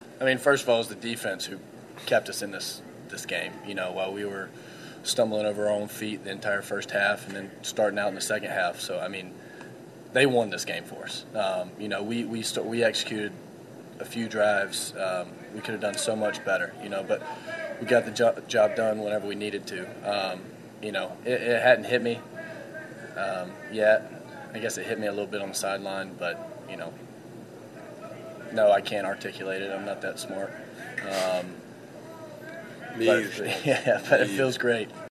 Bennett mentions Georgia’s effort on the defensive side was the key for the Bulldogs erasing the 41-year title drought.